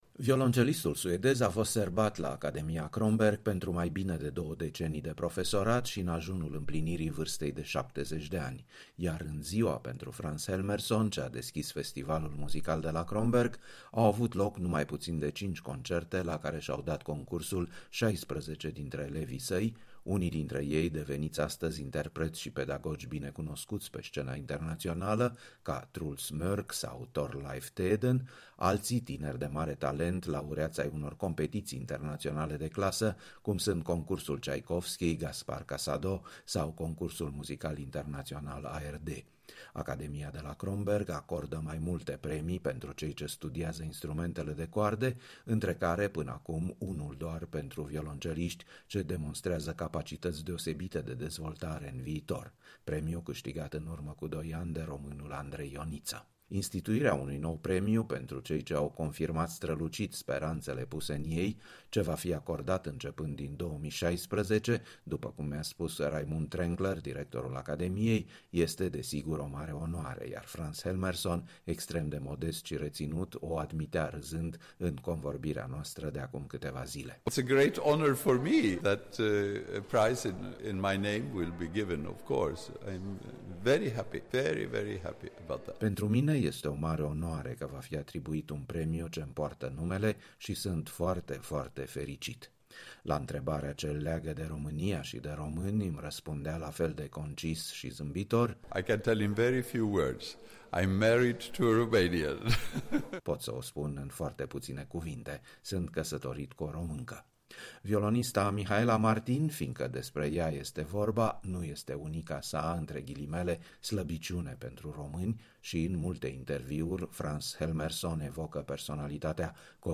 Frans Helmerson pe care îl auziți în încheiere interpretînd un fragment dintr-o Suită de violoncel solo de Bach.
O discuție cu violoncelistul Frans Helmerson la Academia Kronberg